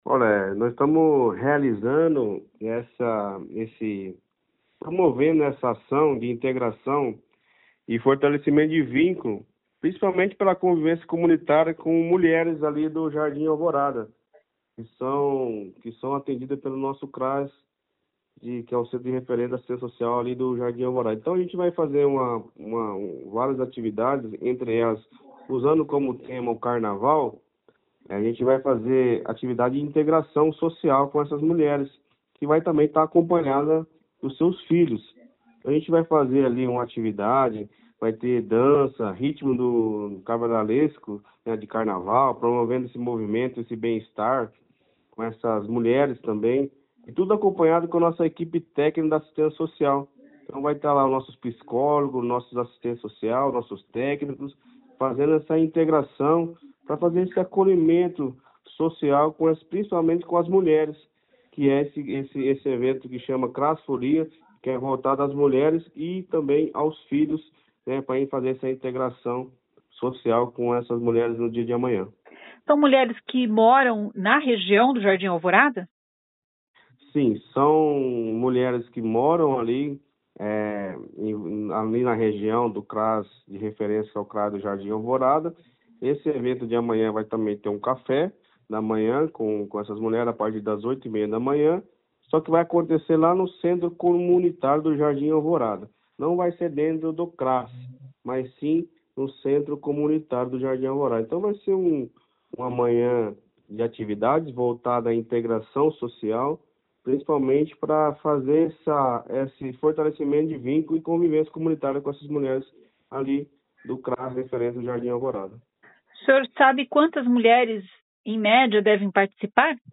O secretário de Assistência Social, Leandro Bravin, diz que a ação será para um grupo de 25 a 60 mulheres e outras ações estão programadas ao longo do ano.